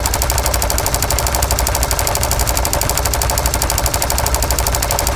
Added more sound effects.
LASRGun_Plasma Rifle Fire Loop_01_SFRMS_SCIWPNS.wav